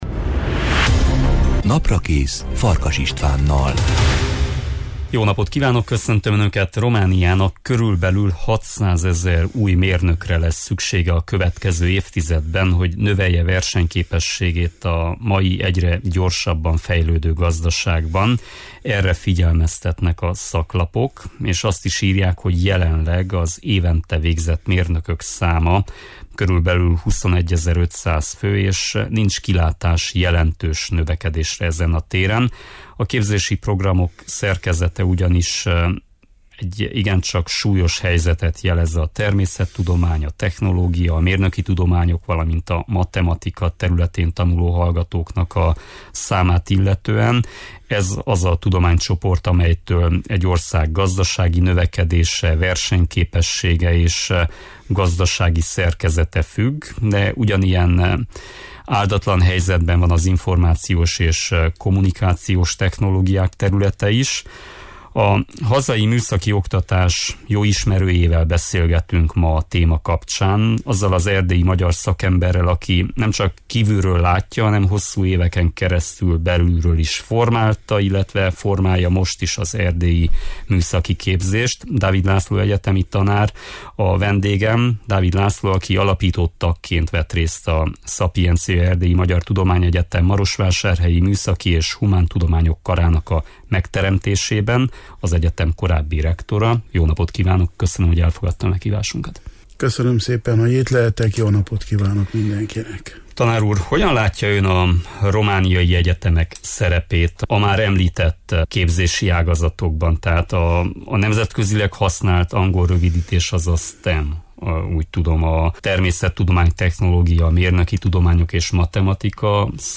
A hazai műszaki oktatás jó ismerőjével, beszélgetünk ma a téma kapcsán, azzal az erdélyi magyar szakemberrel, aki nemcsak kívülről látja, hanem hosszú éveken keresztül belülről is formálta és formálja most is az erdélyi Magyar műszaki képzést.